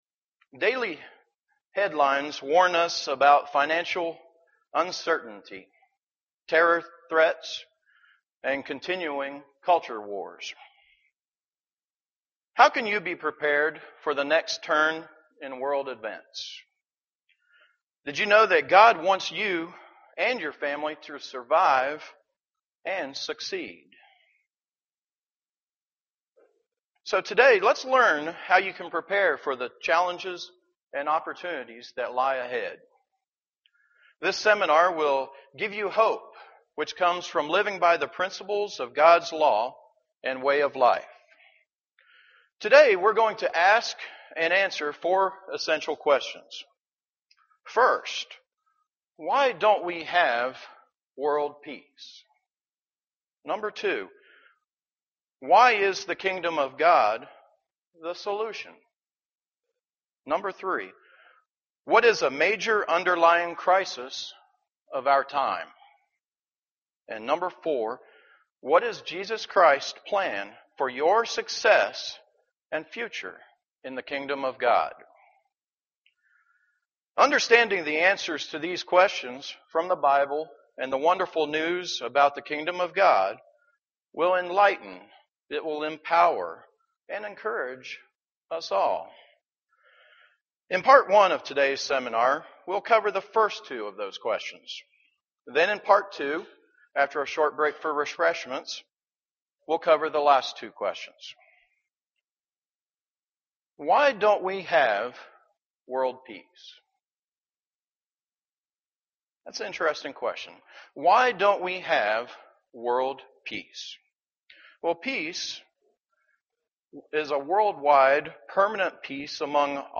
Kingdom of God Bible Seminar. How can we be prepared for the next turn of world events?